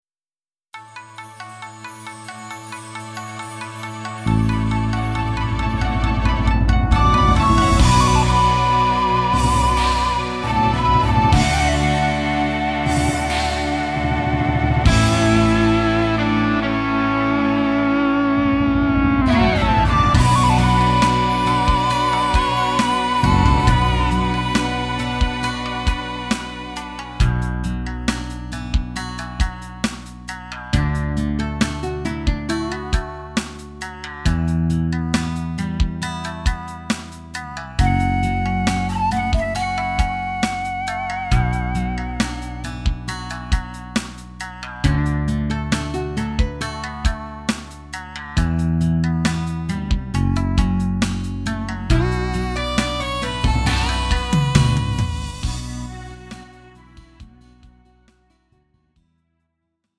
음정 -1키 4:57
장르 가요 구분 Lite MR